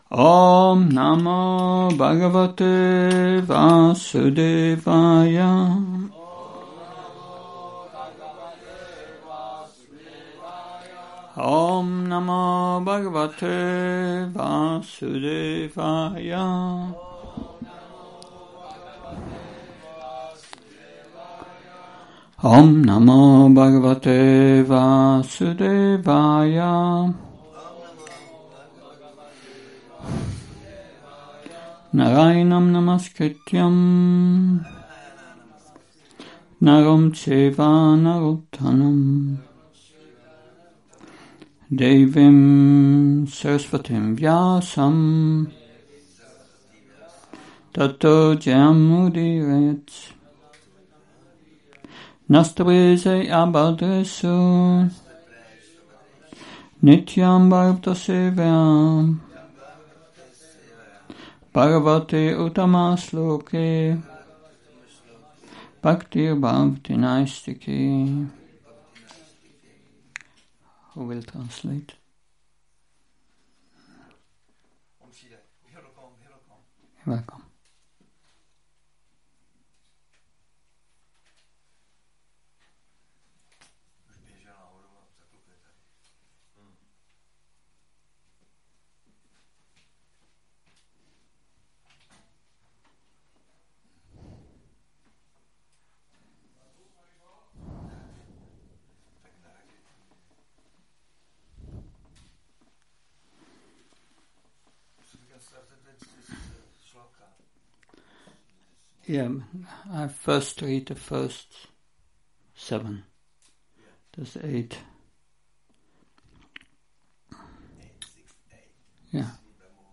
Šrí Šrí Nitái Navadvípačandra mandir